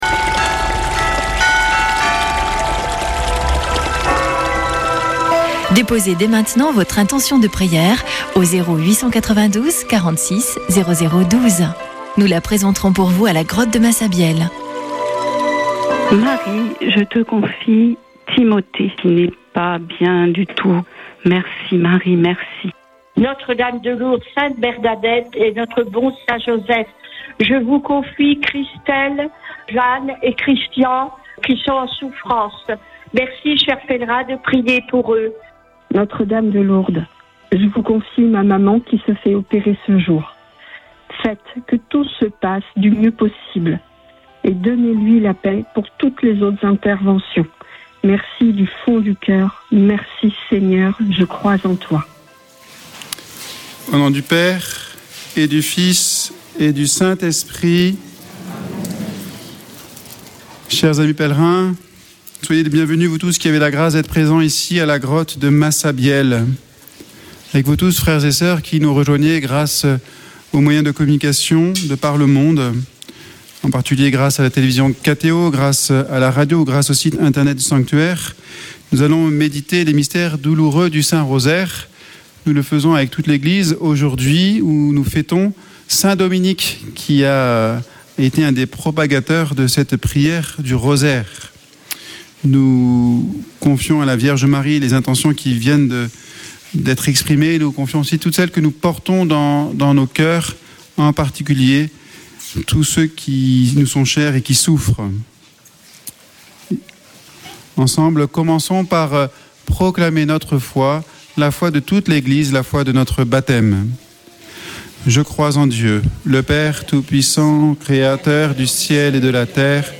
Une émission présentée par Chapelains de Lourdes